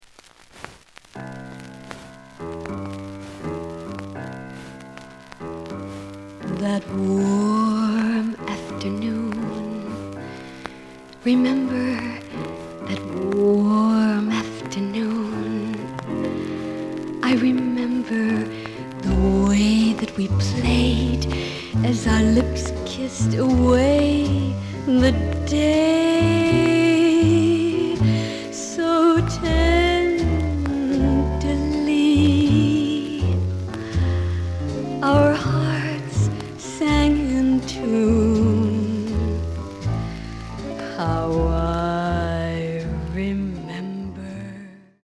The listen sample is recorded from the actual item.
●Genre: Rock / Pop
Some noise on beginning of both sides.)